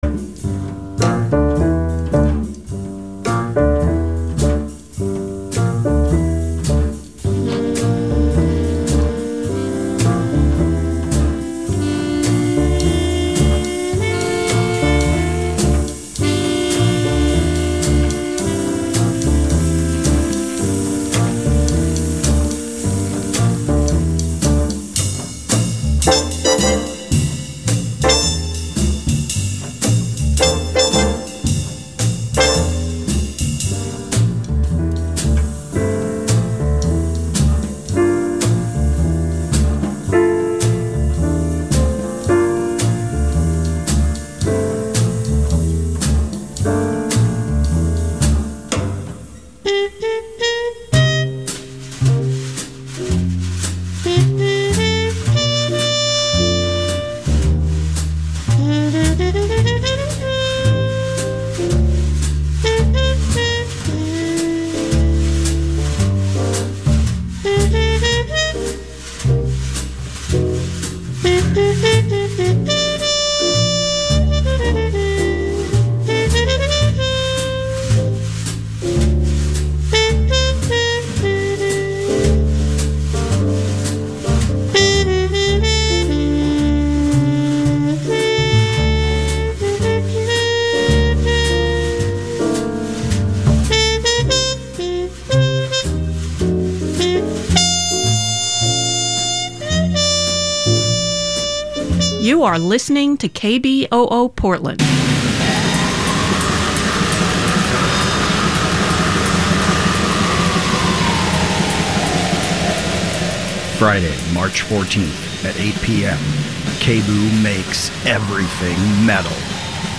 Daily Hip Hop Talk Show